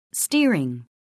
[stíəriŋ]